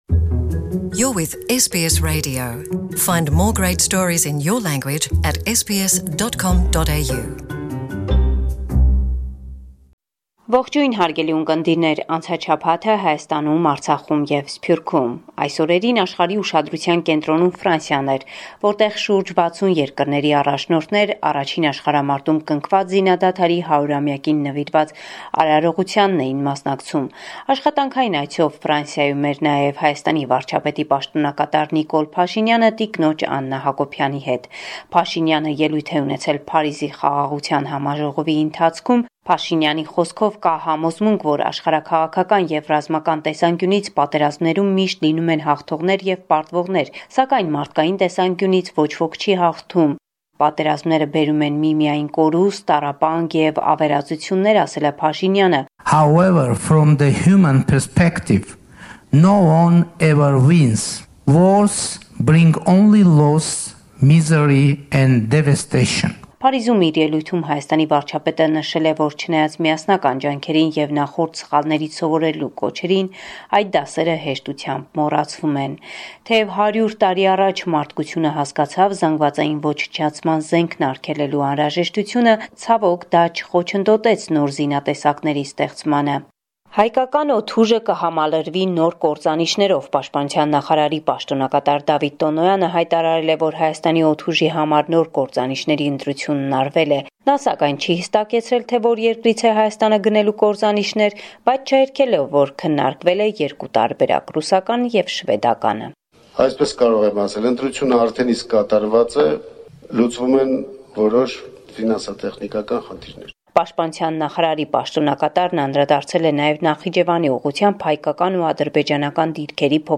Latest News – 13 November 2018